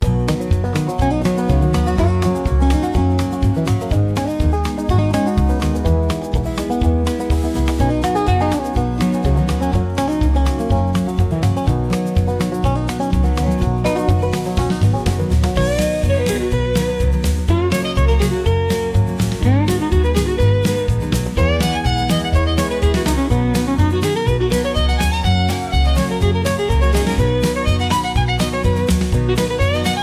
a square dance Patter